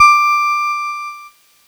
Cheese Note 20-D4.wav